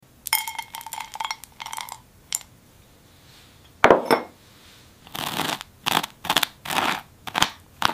Upload By Satisfying AI Asmr
tiny glass blueberry toast spread